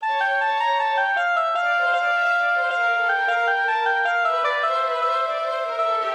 Сэмплер Kontakt-5.
В быстром темпе явно слышно, что атака звука у гобоя и violin ensemble никак не совпадает. Скрипки отстают, звук размытый.
Вот один из примеров: наложение шестнадцатых и восьмых - явно слышно, будто играют пьяные Я даже проверил квантизацию.